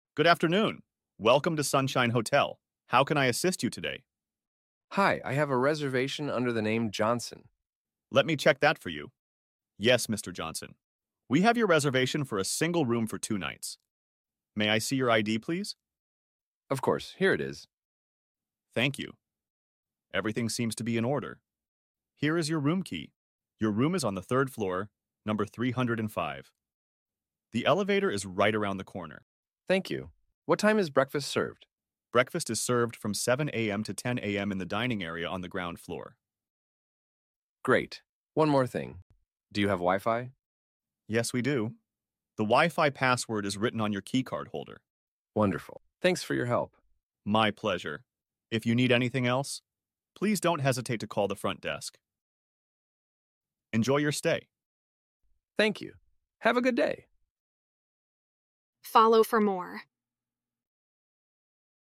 English conversation practice: at the